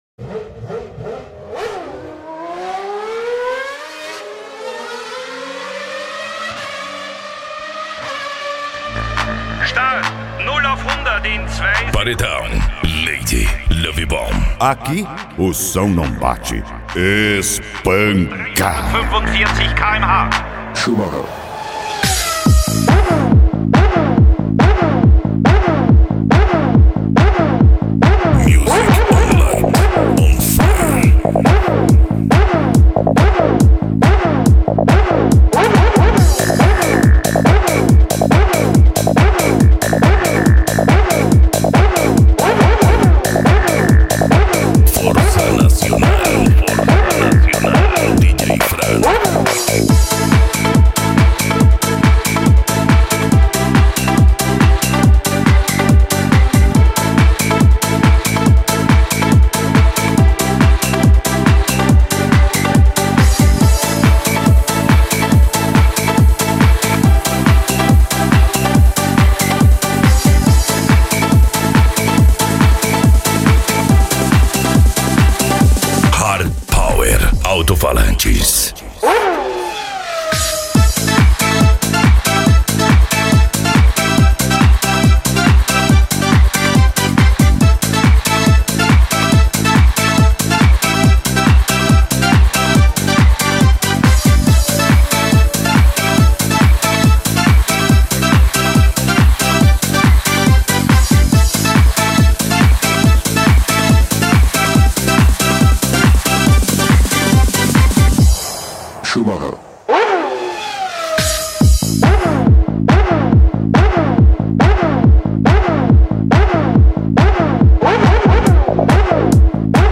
Deep House
Electro House
Eletronica